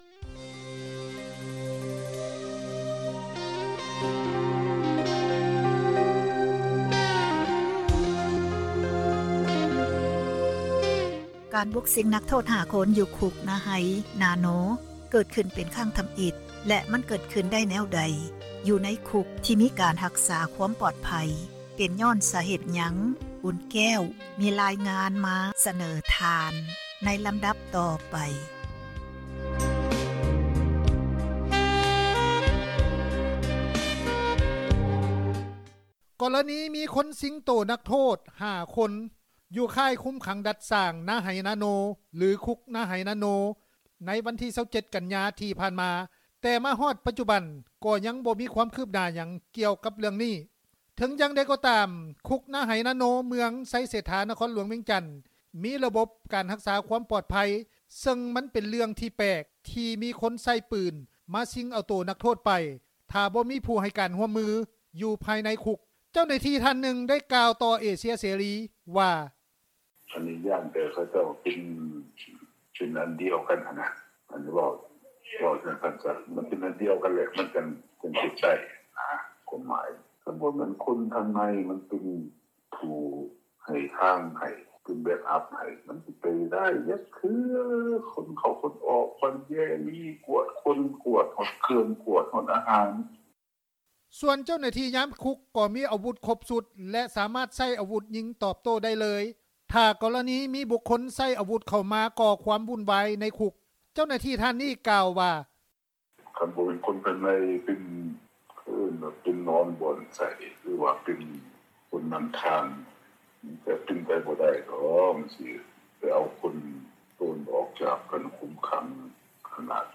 ເຈົ້າໜ້າທີ່ ທ່ານນຶ່ງ ກ່າວຕໍ່ເອເຊັຽເສຣີ ວ່າ:
ປະຊາຊົນ ທ່ານນຶ່ງ ໄດ້ໃຫ້ສໍພາດຕໍ່ເອເຊັຽເສຣີ ວ່າ:
ເຈົ້າໜ້າທີ່ຕໍາຫຼວດອີກທ່ານນຶ່ງ ກ່າວຂໍ້ຄວາມສຽງວ່າ: